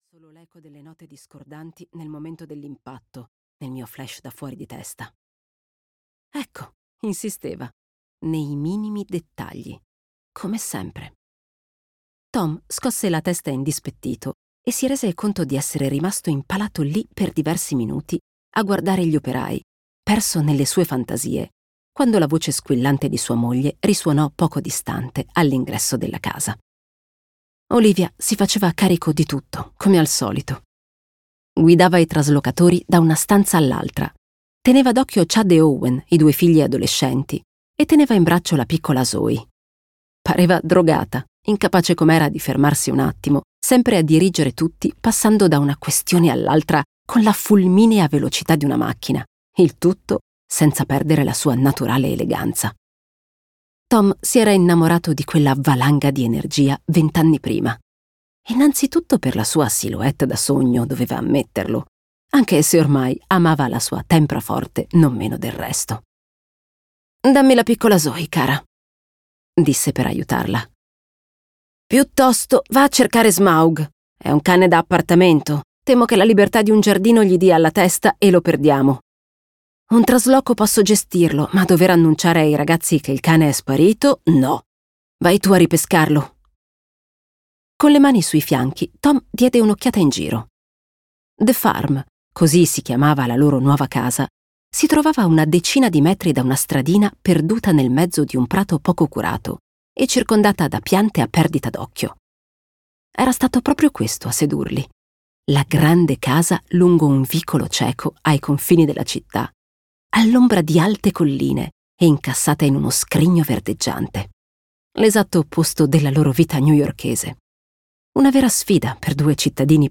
"Il Segnale" di Maxime Chattam - Audiolibro digitale - AUDIOLIBRI LIQUIDI - Il Libraio